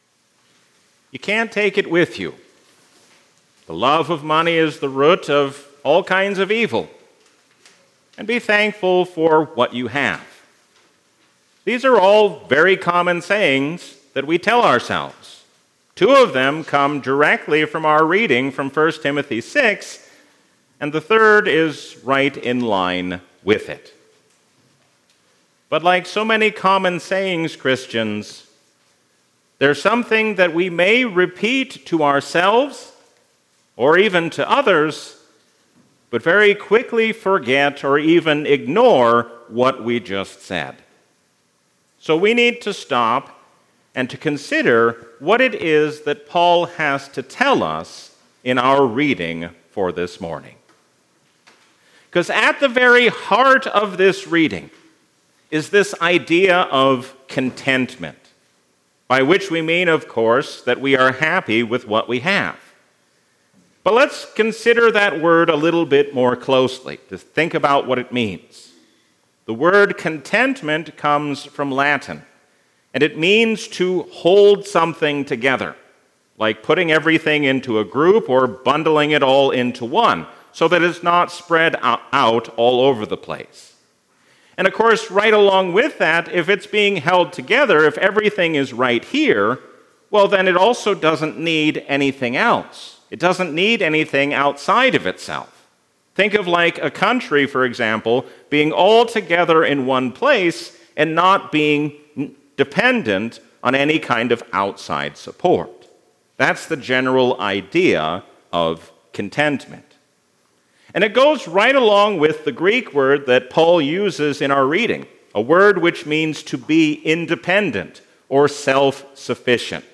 A sermon from the season "Easter 2023."